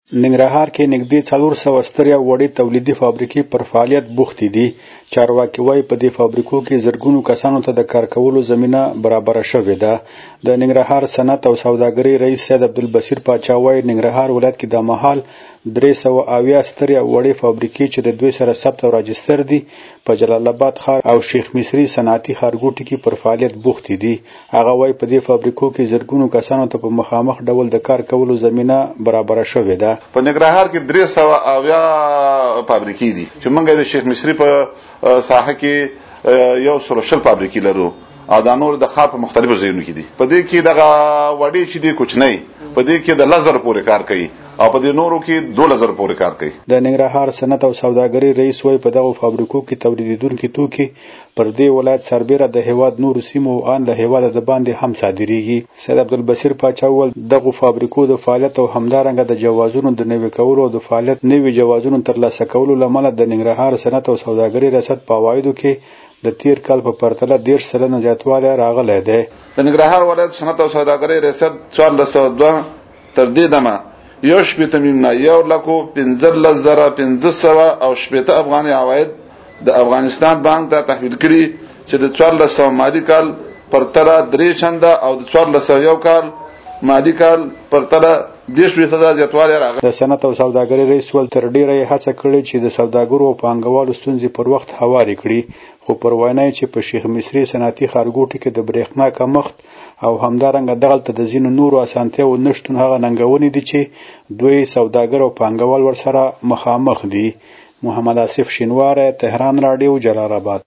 زمونږ خبریال راپور راکړی ننګرها رکې نږدې څلور سوه سترې او وړې تولیدي فابریکې پر فعالیت بوختې دي.